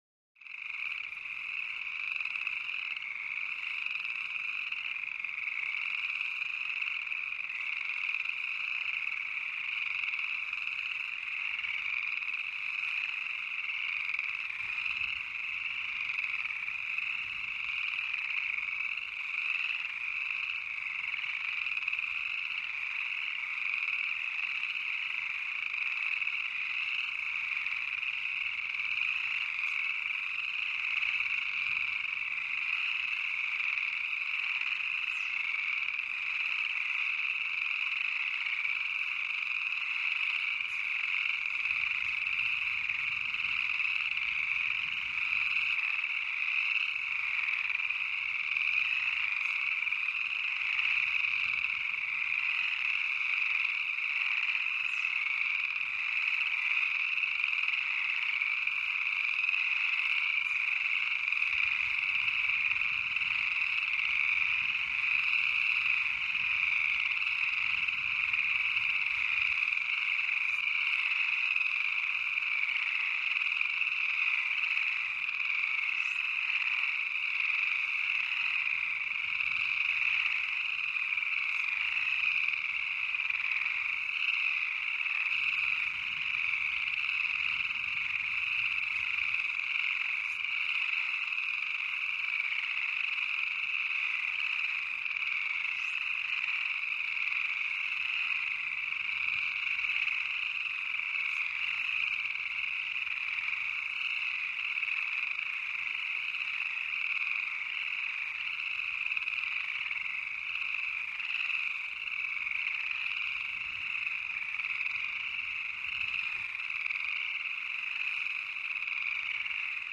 Swamp - Frogs In Pond